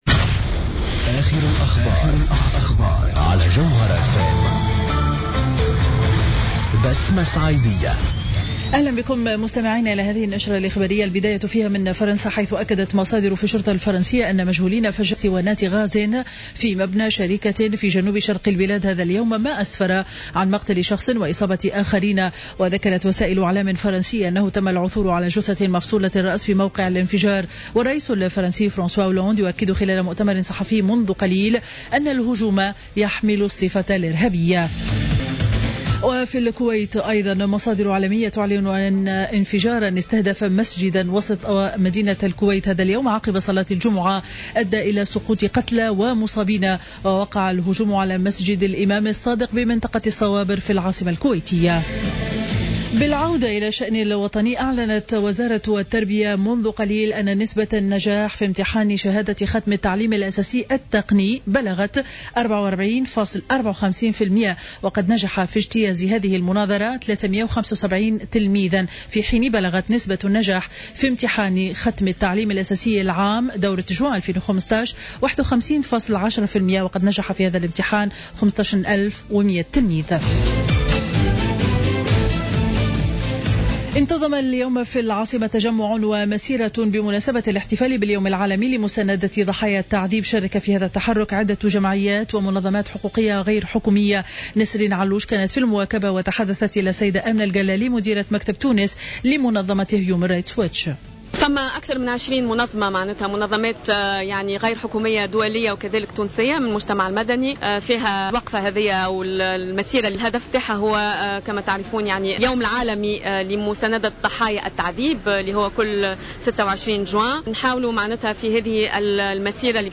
نشرة أخبار منتصف النهار ليوم الجمعة 26 جوان 2015